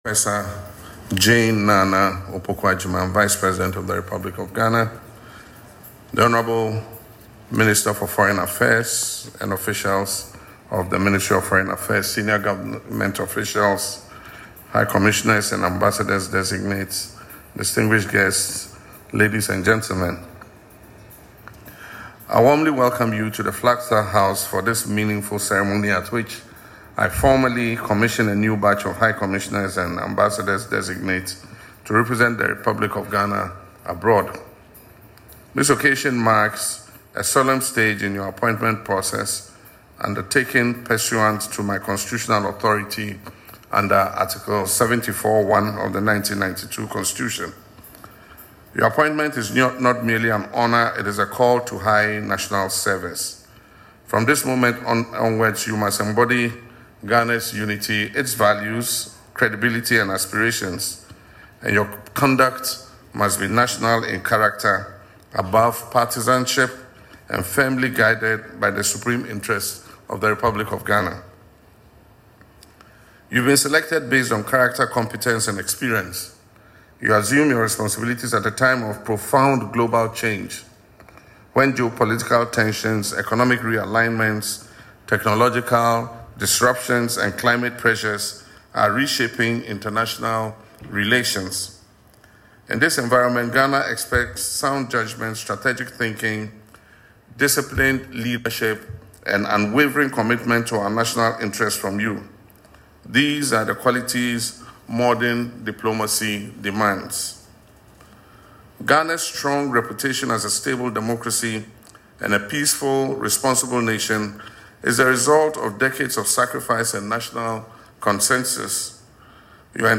The swearing-in ceremony was held at the Jubilee House on Monday, February 9, where President Mahama reminded the envoys that diplomacy goes beyond policy execution and is deeply rooted in character and conduct.
LISTEN TO PRESIDENT MAHAMA IN THE AUDIO BELOW 👇: